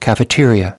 18. cafeteria (n) /ˌkæfəˈtɪriə/: căn tin